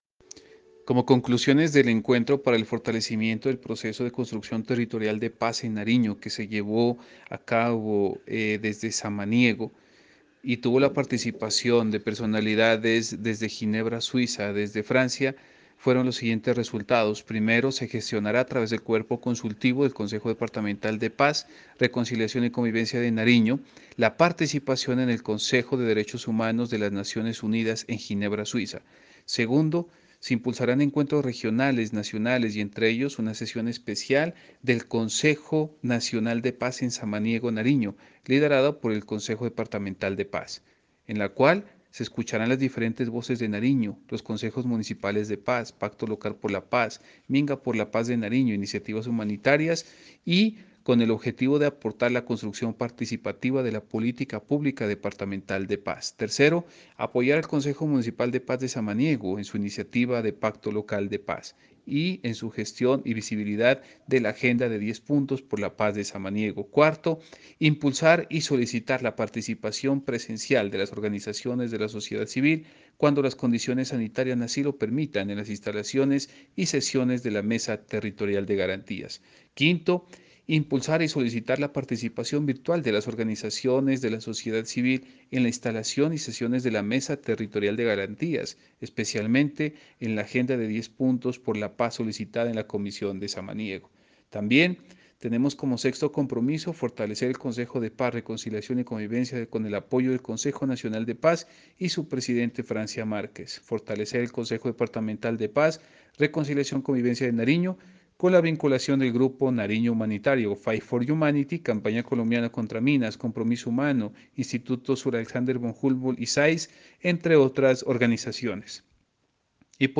Conclusiones del encuentro a cargo del subsecretario de paz y derechos humanos Amilcar Pantoja: